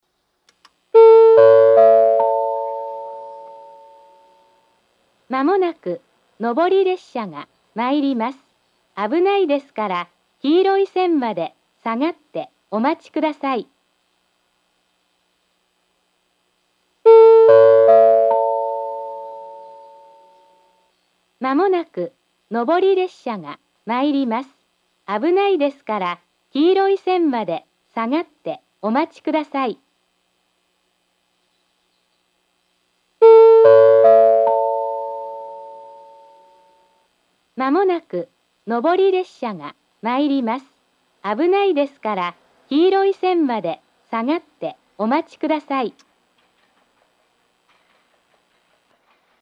１番線上り接近放送